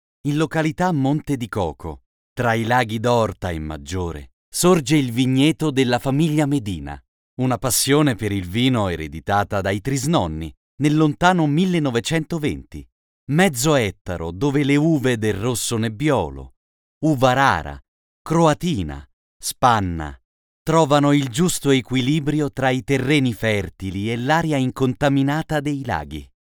Ho una voce calda, profonda, versatile e dinamica!
Sprechprobe: eLearning (Muttersprache):
I have a warm, deep, versatile and dynamic voice!
Chiaro, neutro, sofisticato.mp3